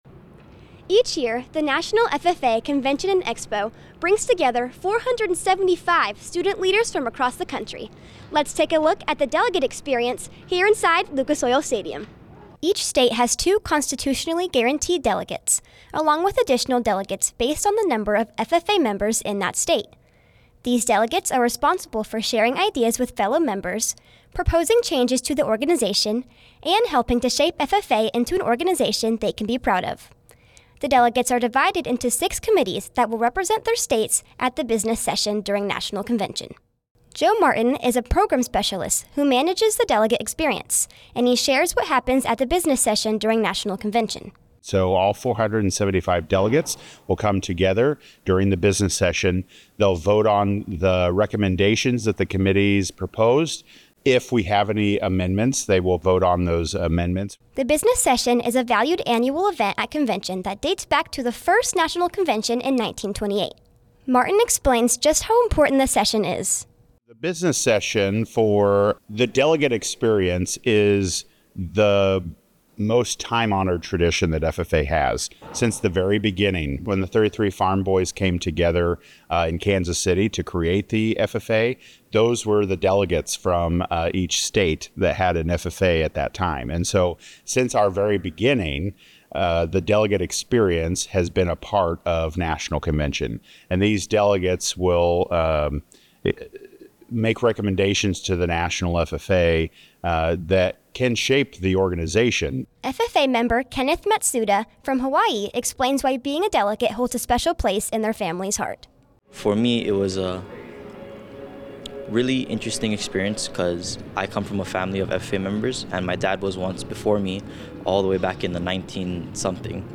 NRV-034_delegate-experience_RADIO.mp3